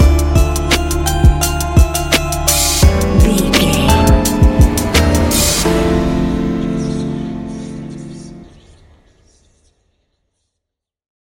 Ionian/Major
D
chilled
laid back
Lounge
sparse
new age
chilled electronica
ambient
atmospheric
morphing
instrumentals